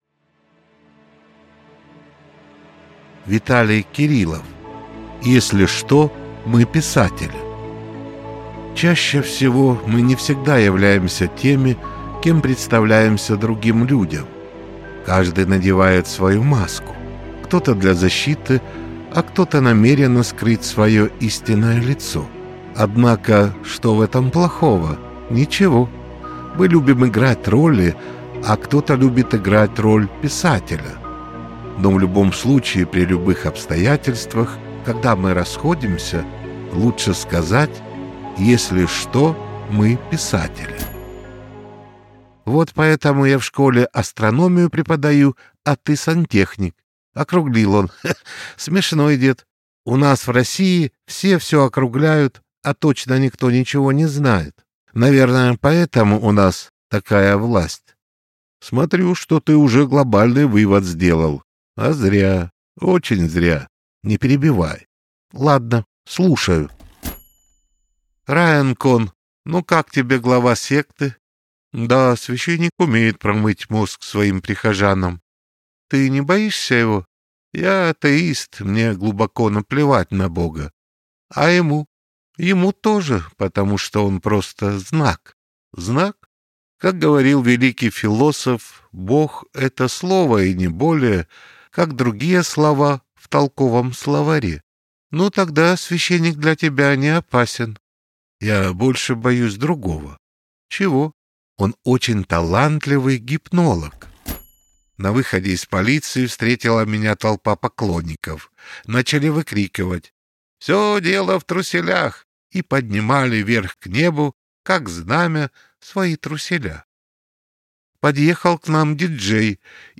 Аудиокнига Если что – мы писатели!